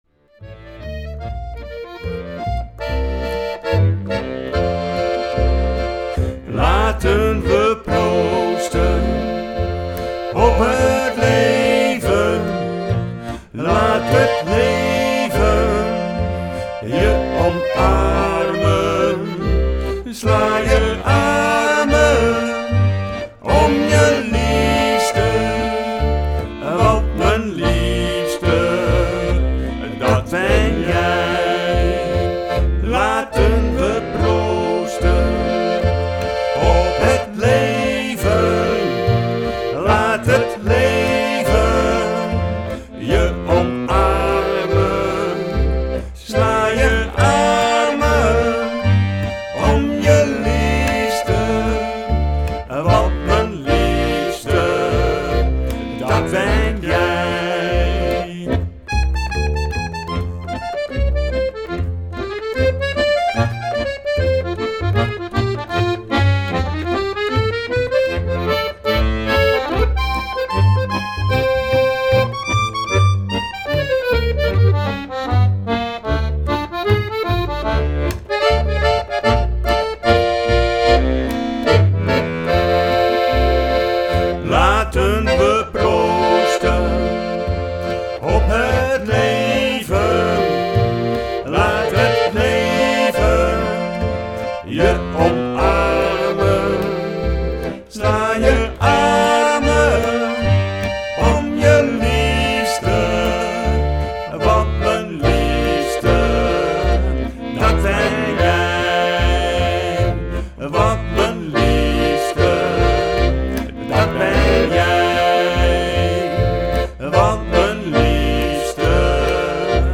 hieronder zijn vocale liedjes te beluisteren